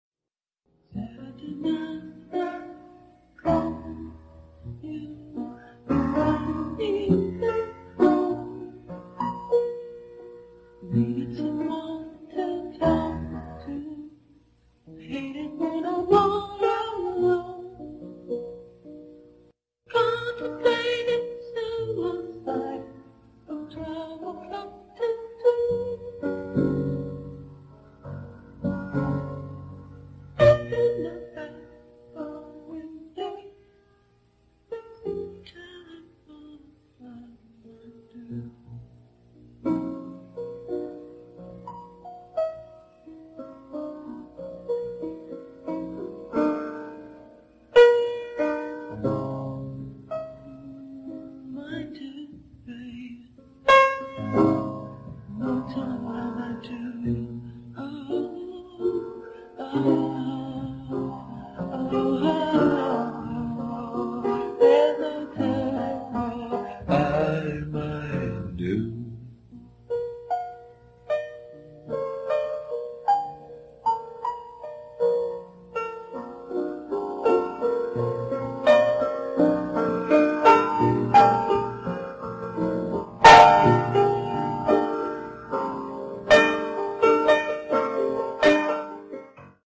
in his home studio